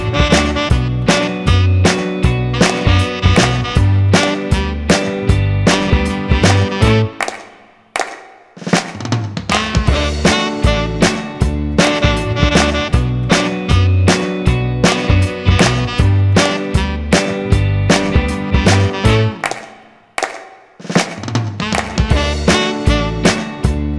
no Backing Vocals Rock 'n' Roll 2:44 Buy £1.50